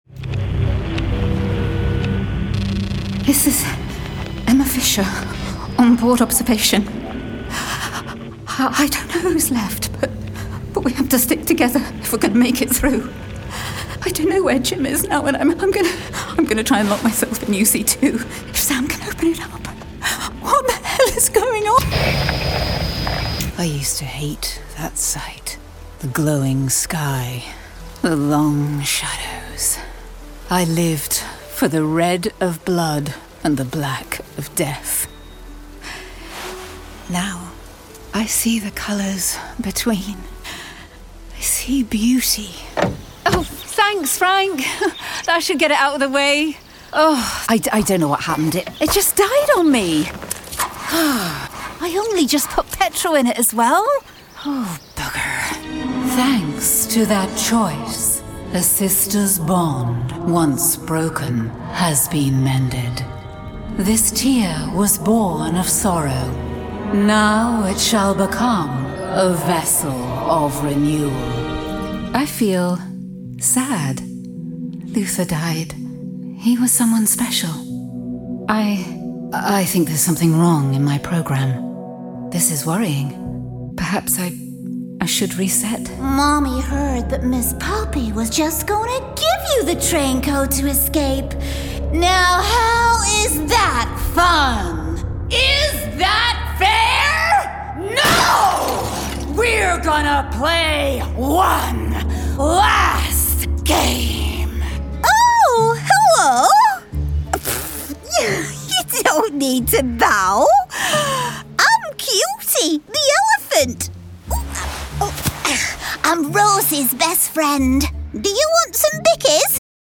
A Classic English Rose Voice
Gaming Demo
English RP, Northern, Liverpool, London, West Country, General American, Australian.
Middle Aged
New Gaming Demo.mp3